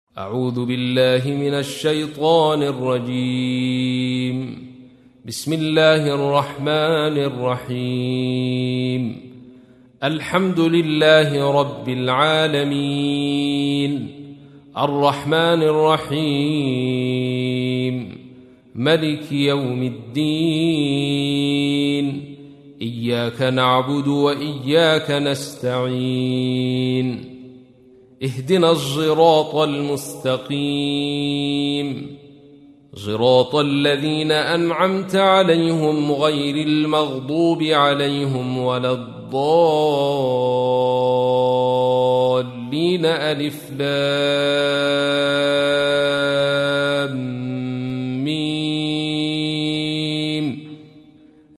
تحميل : 1. سورة الفاتحة / القارئ عبد الرشيد صوفي / القرآن الكريم / موقع يا حسين